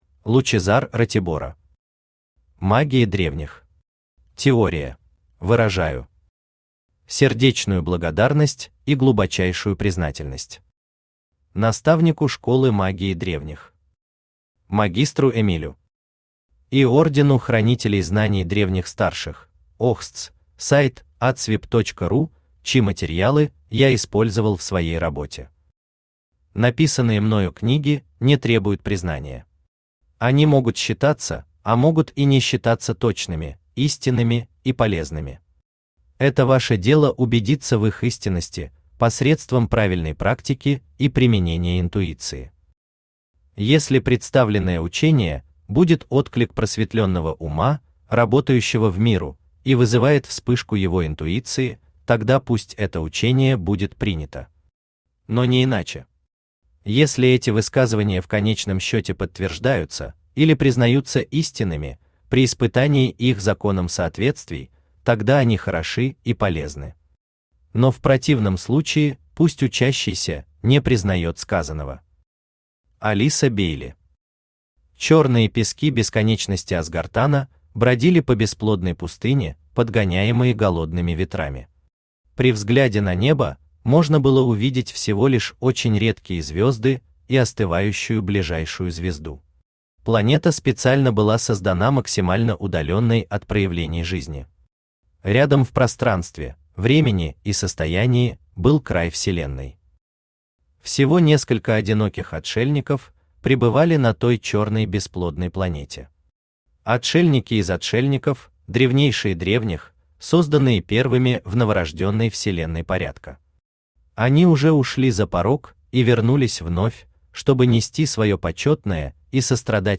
Аудиокнига Магия Древних. Теория | Библиотека аудиокниг
Aудиокнига Магия Древних. Теория Автор Лучезар Ратибора Читает аудиокнигу Авточтец ЛитРес.